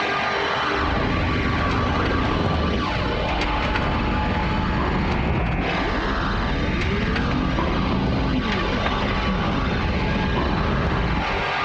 tesla-turret-rotation-loop.ogg